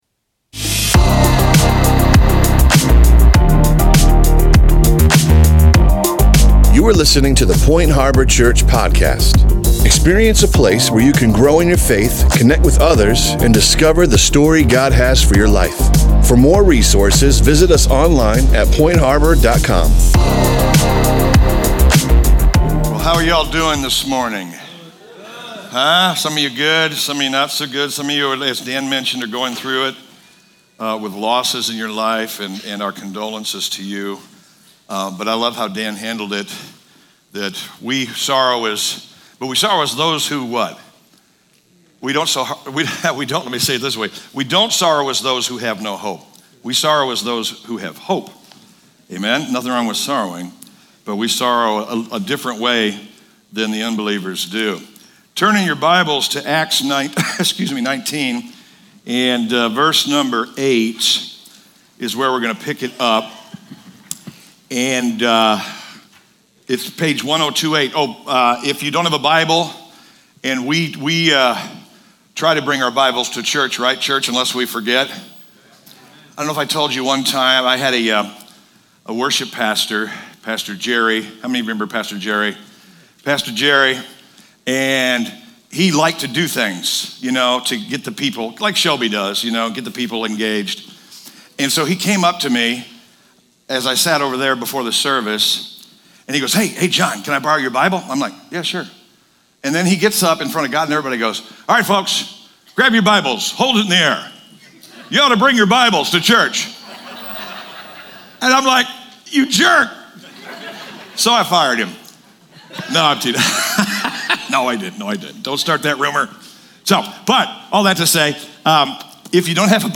Podcast (sermons): Play in new window | Download